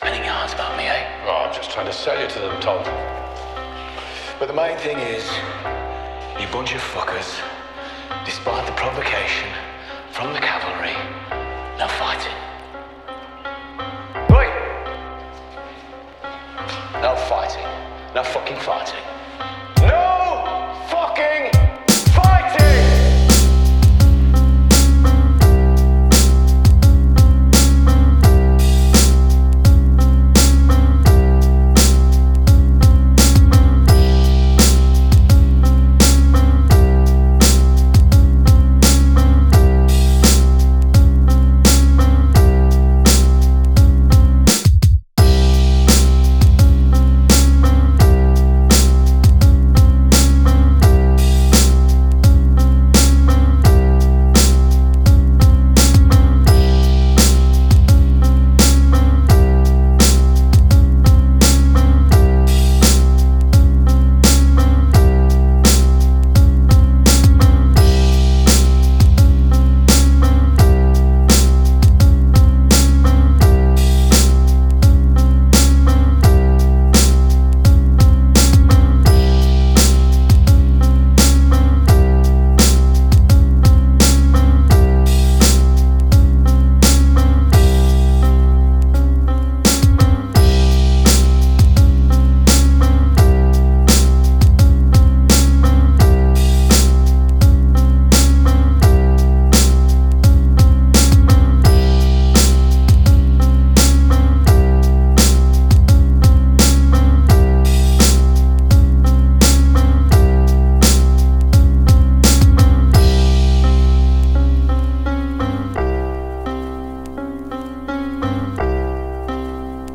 GRATUITAChillLo-Fi
BPM84
NOTAEm
GÉNEROHip-Hop